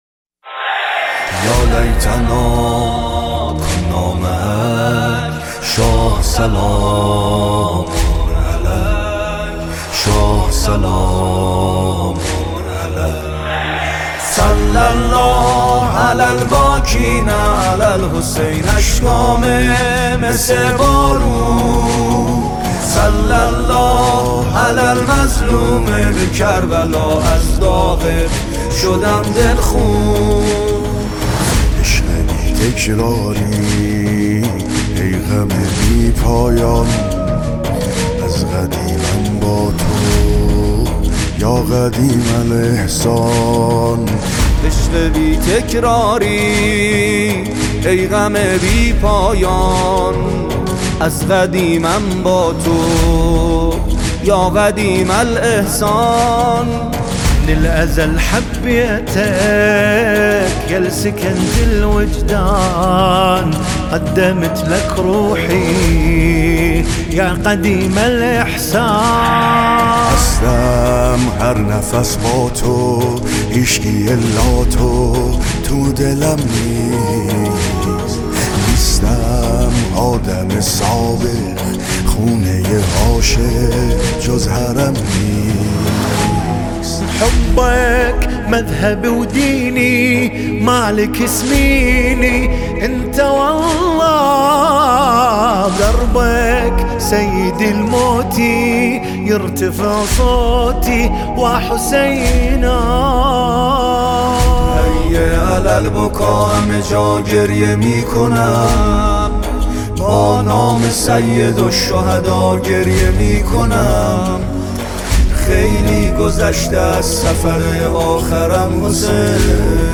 کربلایی محمدحسین پویانفر | اربعین حسینی | موکب محبان علی ابن ابی طالب (ع) کربلا